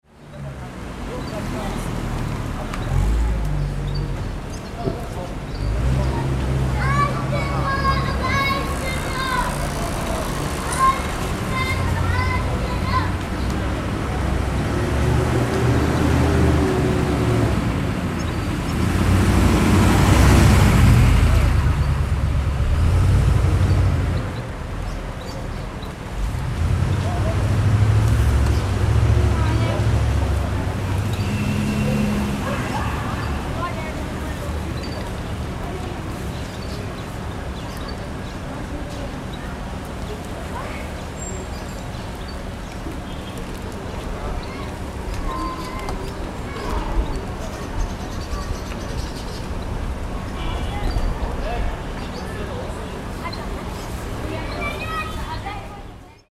syriast-ambient_outdoors.mp3